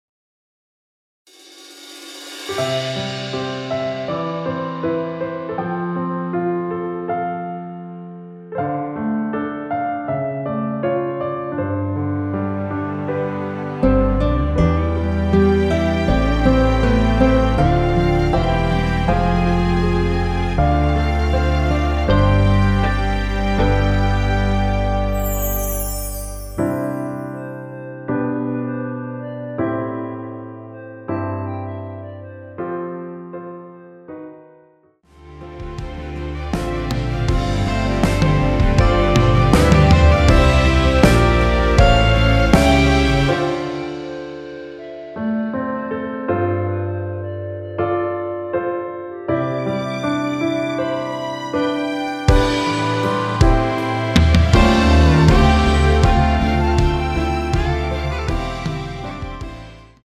원키에서(-1)내린 멜로디 포함된 (1절+후렴) MR입니다.
노래방에서 노래를 부르실때 노래 부분에 가이드 멜로디가 따라 나와서
앞부분30초, 뒷부분30초씩 편집해서 올려 드리고 있습니다.
중간에 음이 끈어지고 다시 나오는 이유는